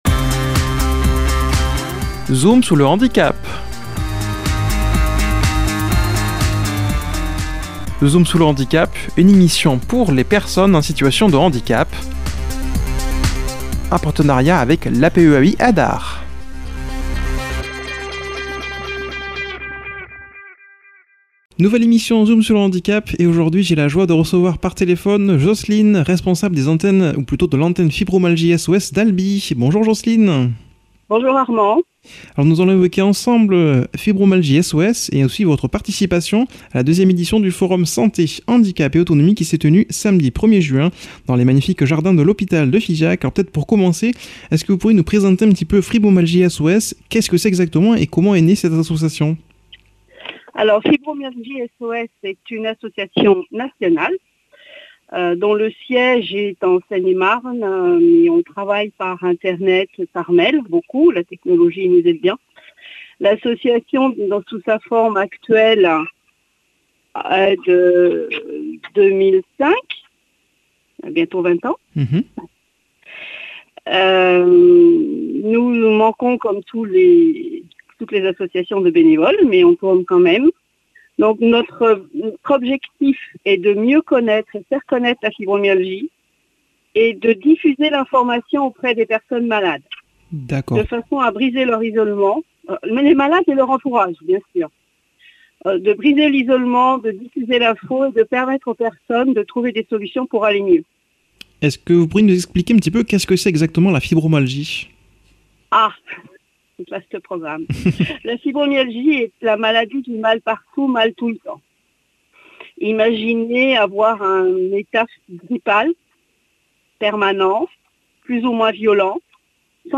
qui aujourd’hui a comme invitée par téléphone